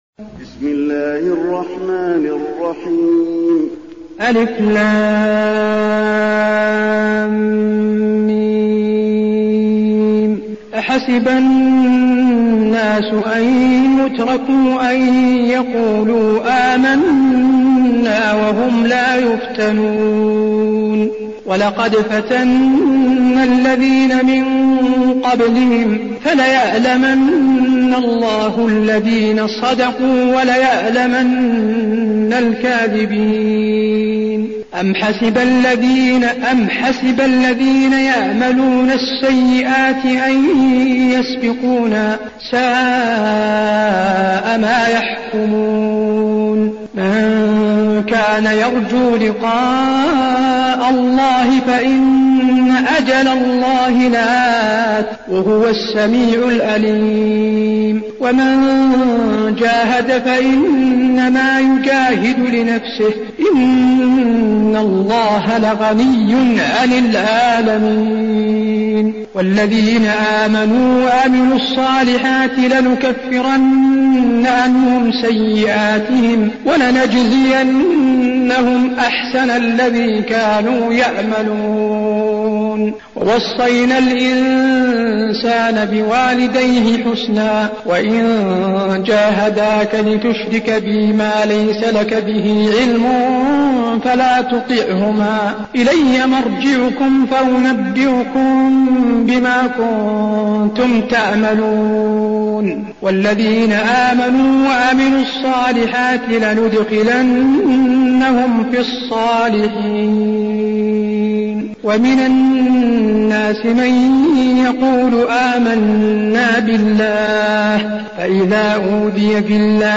المكان: المسجد النبوي العنكبوت